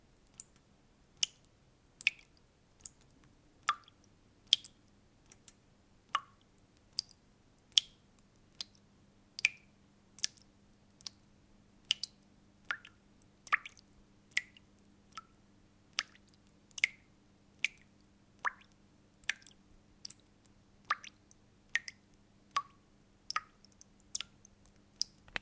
جلوه های صوتی
دانلود صدای ریختن آب در لیوان 2 از ساعد نیوز با لینک مستقیم و کیفیت بالا